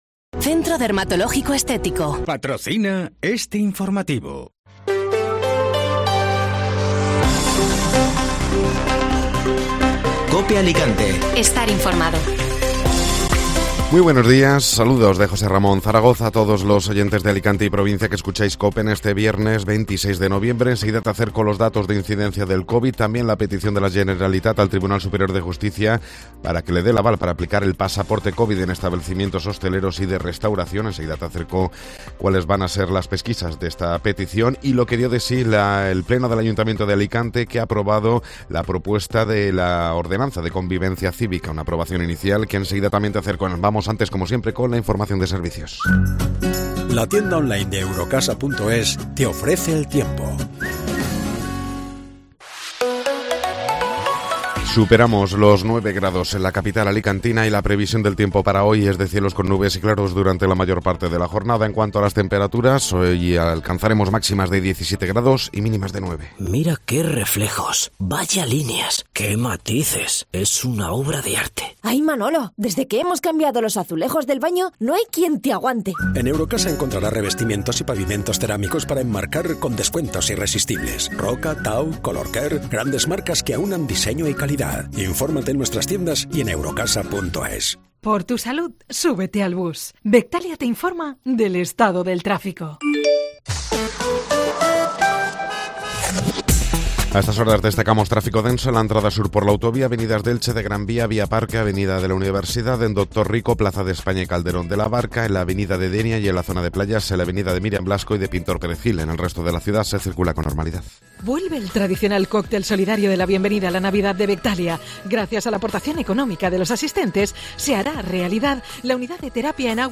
Informativo Matinal (Viernes 26 de Noviembre)